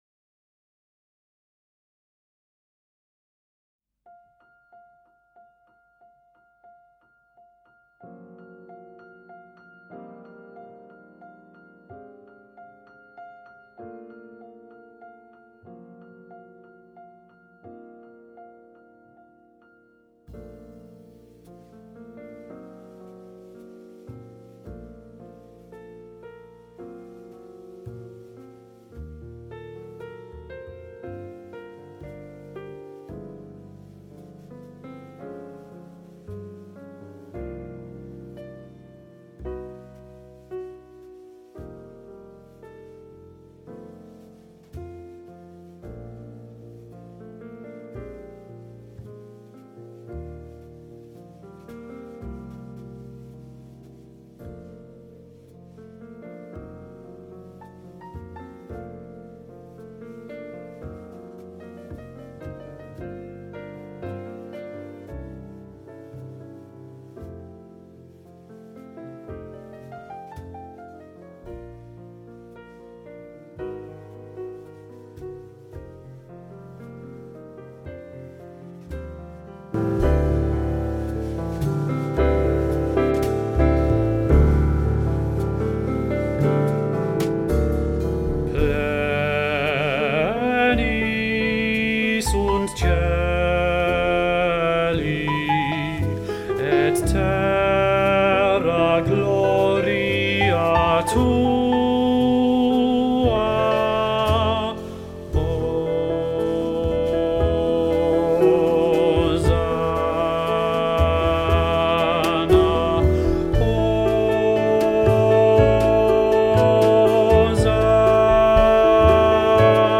Sanctus Bass | Ipswich Hospital Community Choir
Sanctus-Bass.mp3